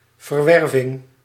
Ääntäminen
IPA: /a.ʃa/